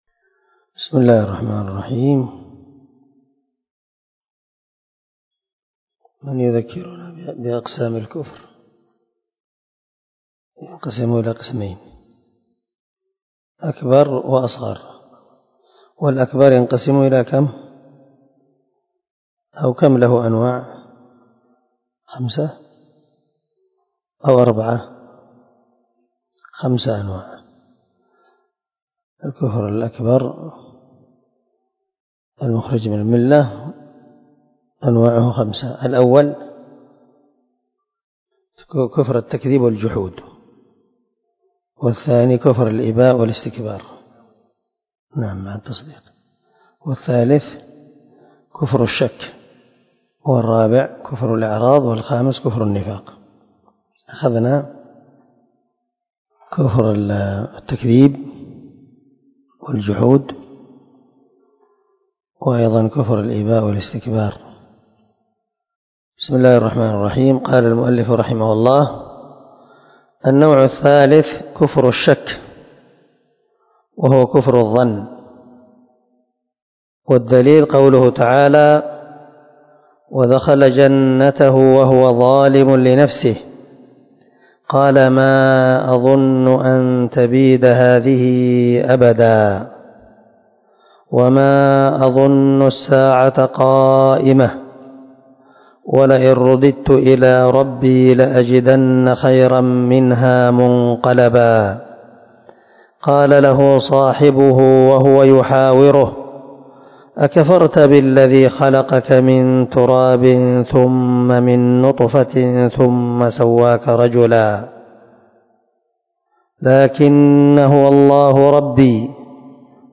🔊الدرس 33 النوع الثالث كفر الشك